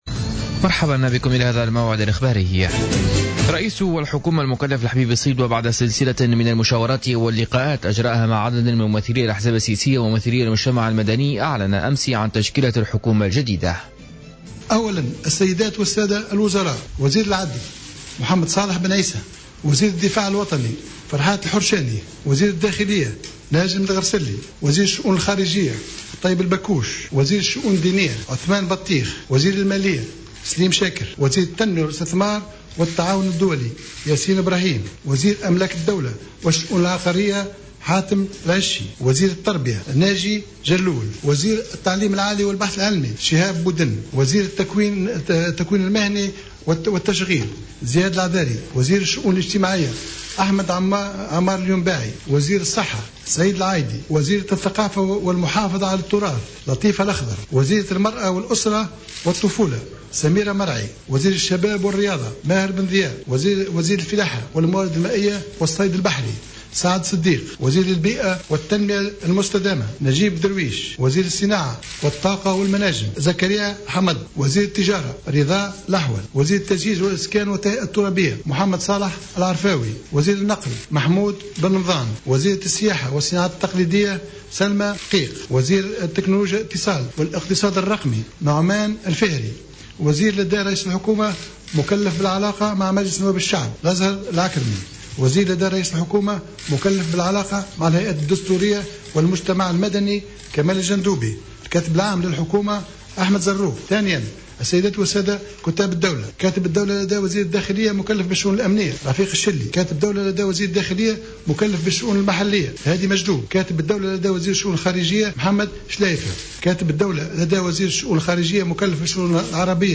نشرة أخبار منتصف الليل ليوم الثلاثاء 03-02-15